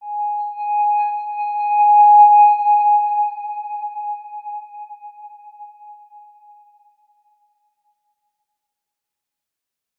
X_Windwistle-G#4-mf.wav